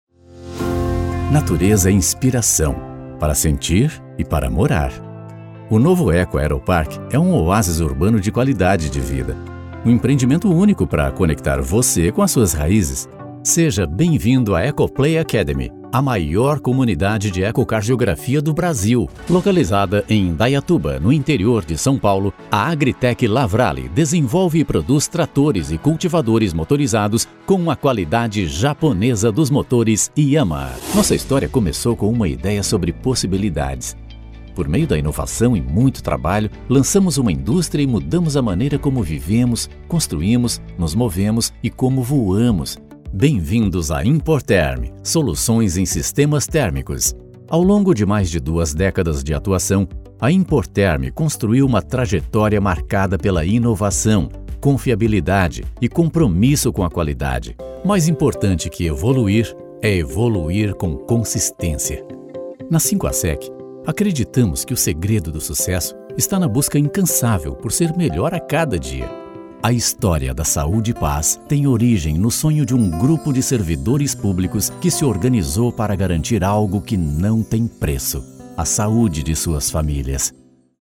Vídeos corporativos
Mi voz es profunda, amigable, natural y conversacional.
Trabajo desde mi propio estudio profesionalmente equipado y con tratamiento acústico.